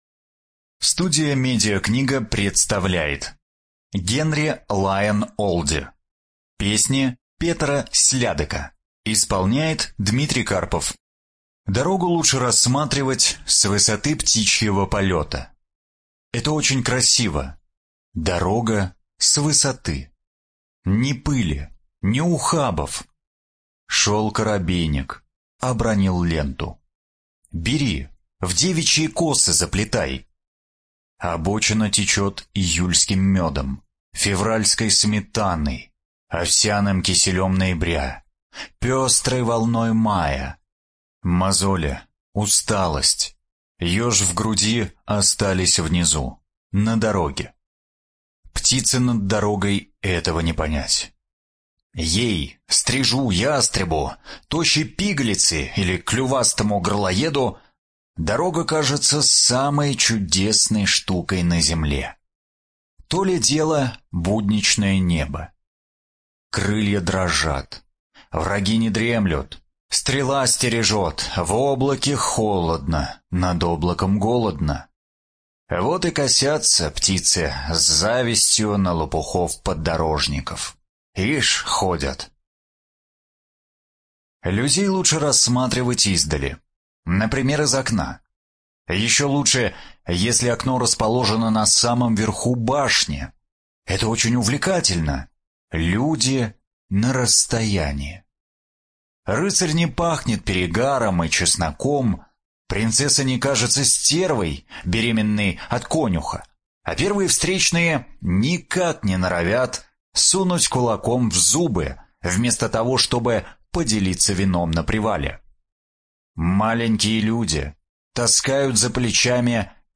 ЖанрФэнтези
Студия звукозаписиМедиакнига